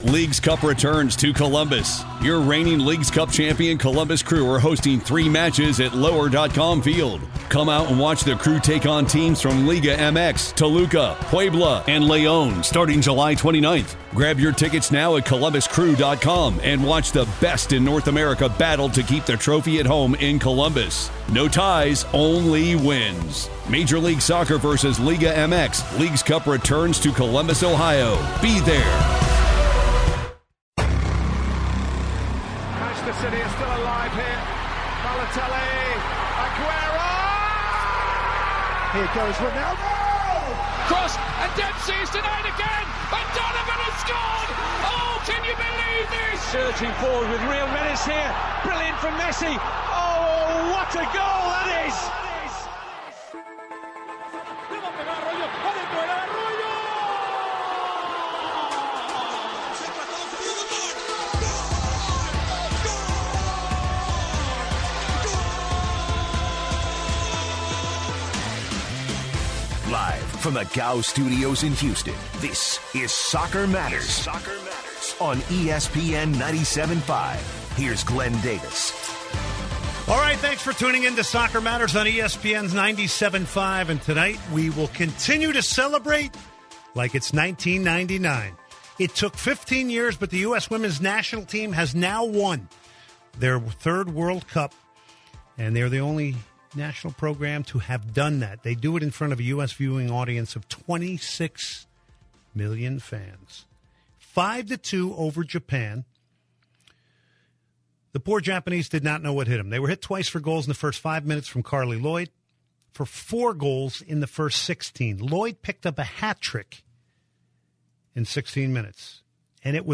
takes calls from listeners to get their opinions on the USWNT win and whether this will have lasting effect on attendance and attention given to soccer in the US.